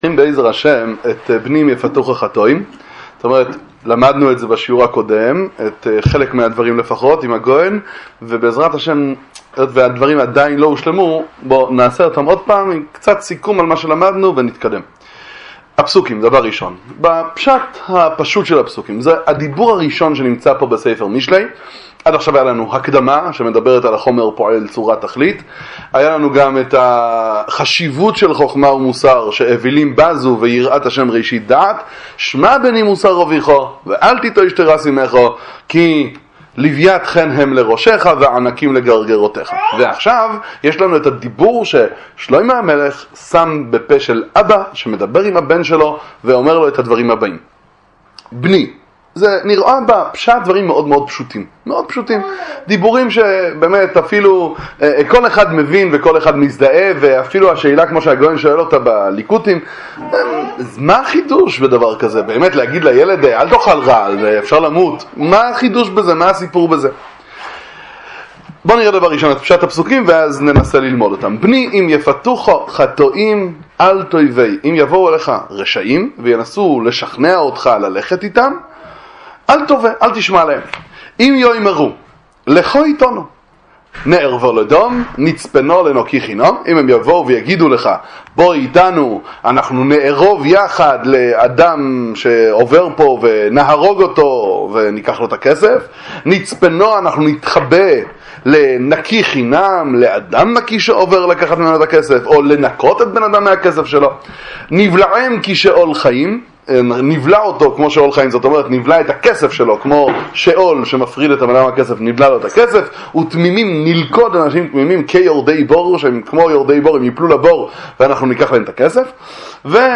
שיעורי תורה לצפיה בספרי הגר"א, דברי חיזוק ומוסר בהבנת נפש האדם, שיעורים בספרי מוסר